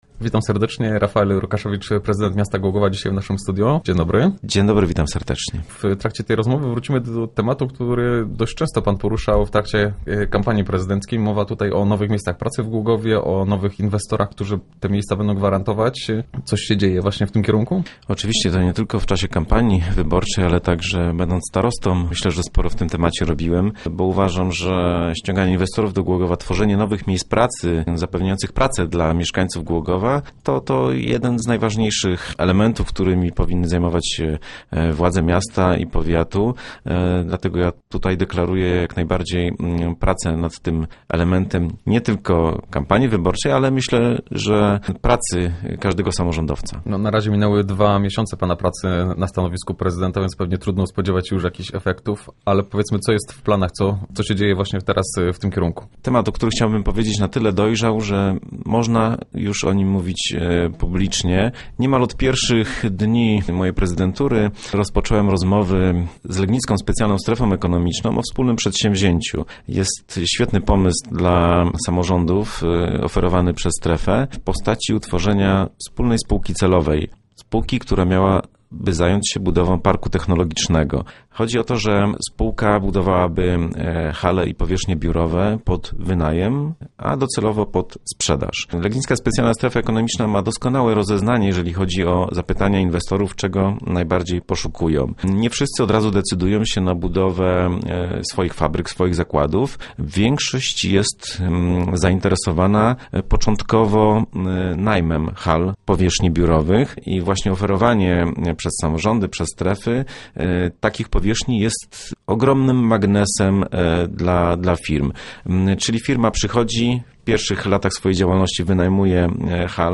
Start arrow Rozmowy Elki arrow Rokaszewicz: O takim rozwiązaniu myślałem od lat